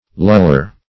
luller - definition of luller - synonyms, pronunciation, spelling from Free Dictionary Search Result for " luller" : The Collaborative International Dictionary of English v.0.48: Luller \Lull"er\, n. One who, or that which, lulls.